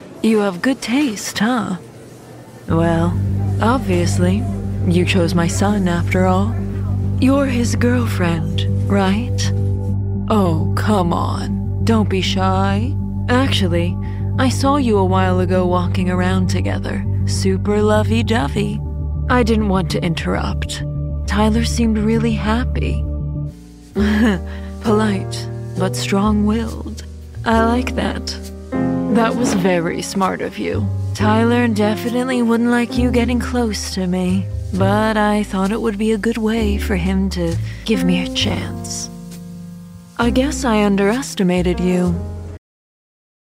hell, fein, zart, sehr variabel
Jung (18-30)
Audio Drama (Hörspiel), Audiobook (Hörbuch), Game, Scene, Tale (Erzählung)